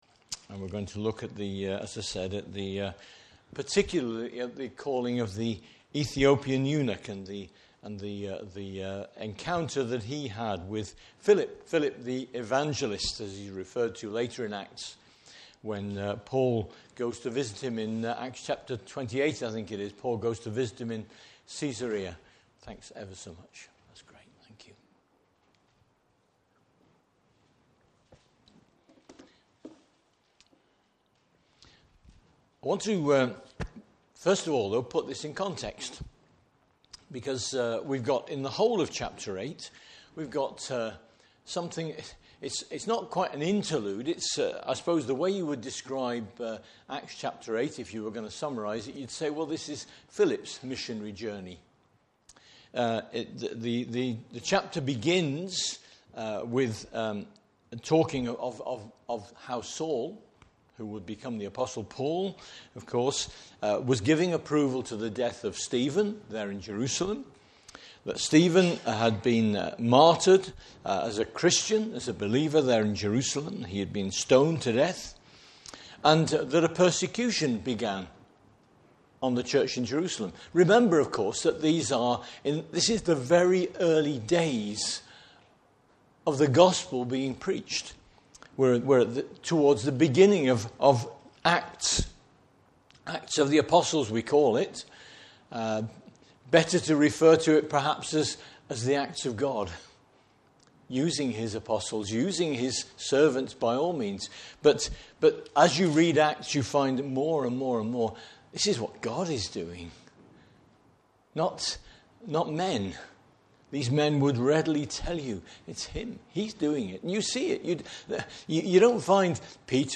Service Type: Morning Service Bible Text: Acts 8:26-40.